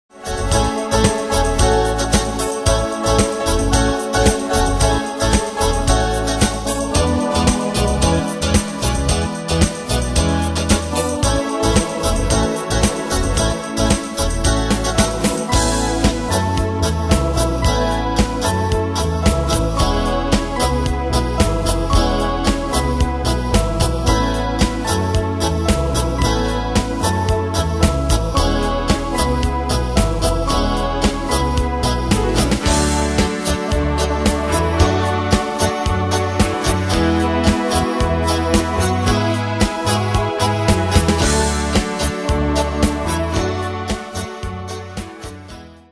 Demos Midi Audio
Danse/Dance: Continental Cat Id.
Pro Backing Tracks